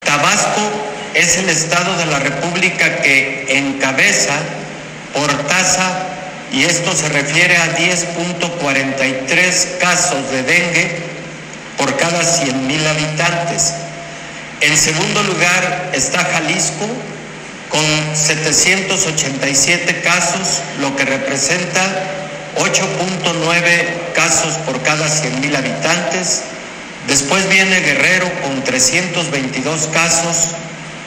Jalisco ocupa actualmente el segundo lugar a nivel nacional en número de casos de dengue, solo por debajo de Tabasco, confirmó el secretario de salud Jalisco, Héctor Raúl Pérez Gómez.